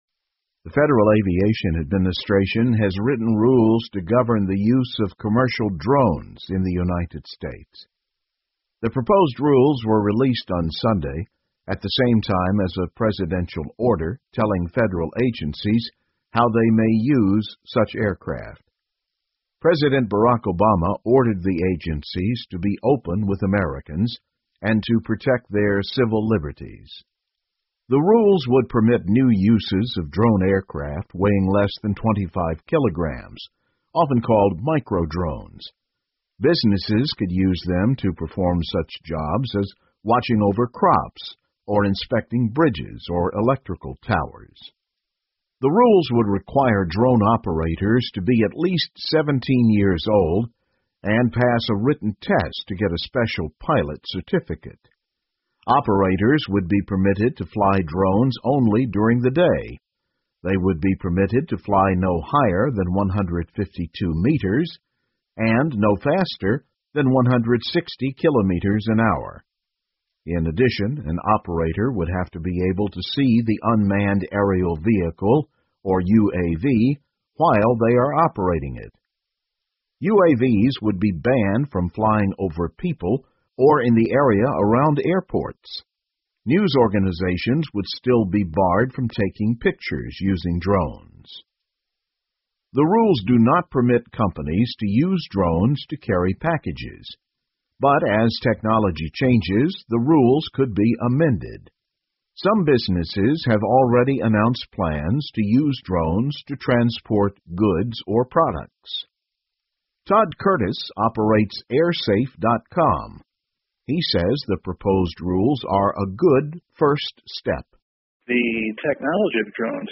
VOA慢速英语2015 美国起草无人机商业用途条例 听力文件下载—在线英语听力室